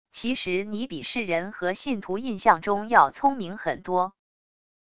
其实你比世人和信徒印象中要聪明很多wav音频生成系统WAV Audio Player